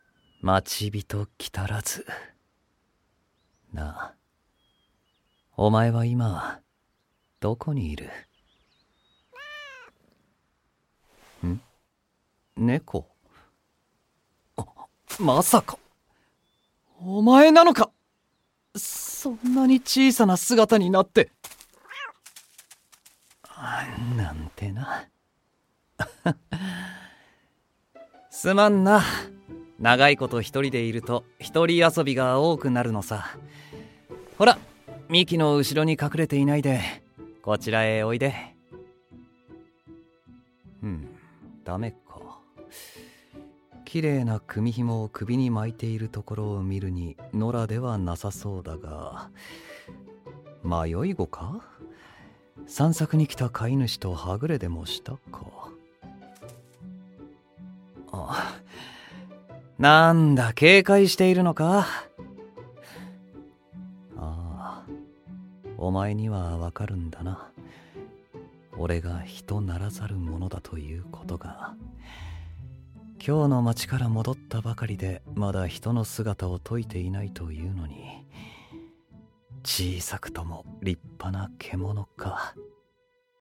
鬼ノ待人 サンプルボイス01